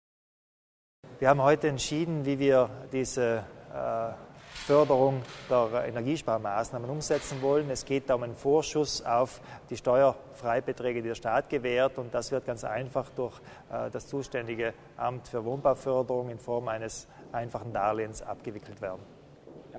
Landeshauptmann Kompatscher erläutert die Neuigkeiten im Bereich Wohnbauförderung